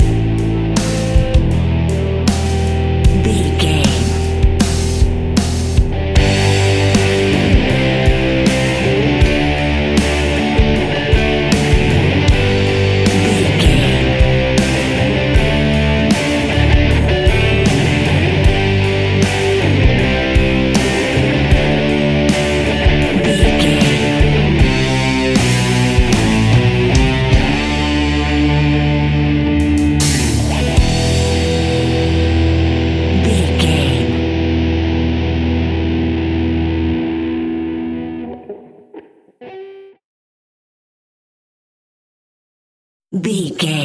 Epic / Action
Aeolian/Minor
hard rock
blues rock
rock guitars
Rock Bass
Rock Drums
heavy drums
distorted guitars
hammond organ